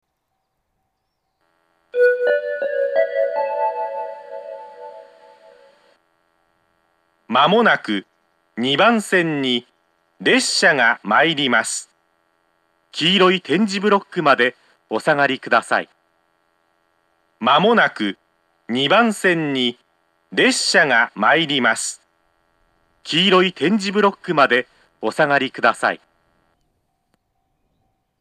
２０１９年１２月１６日には放送装置が更新され、発車メロディーの音質が向上し、自動放送が巌根・館山型に変更されました。
２番線接近放送
fusa2bansen-sekkin2.mp3